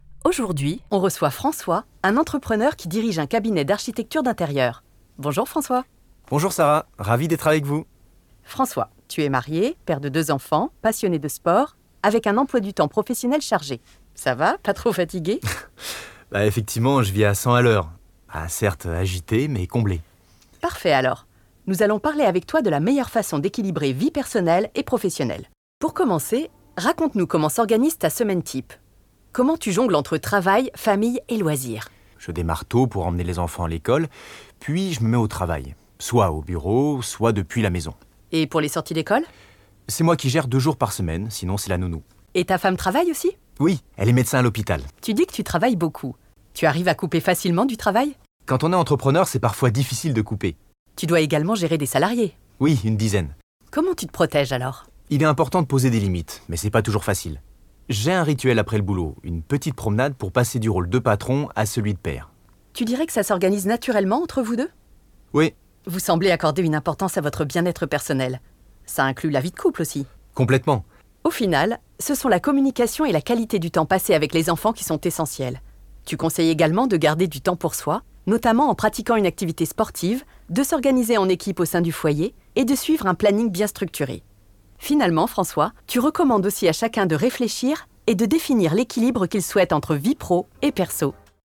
Interview entrepreneur
voix amicale voix journalistique voix naturelle